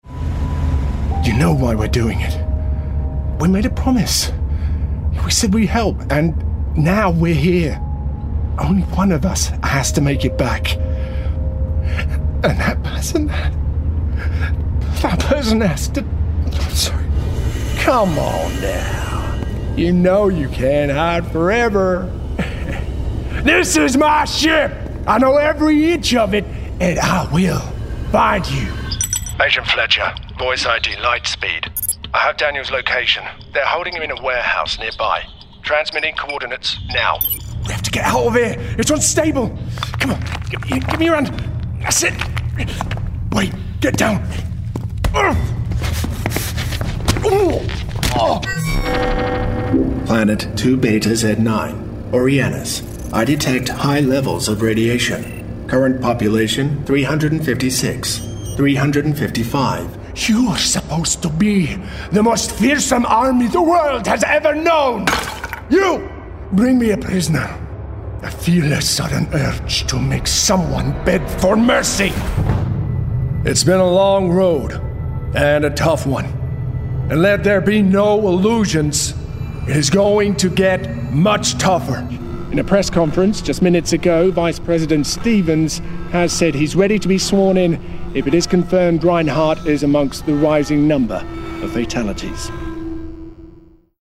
Showreel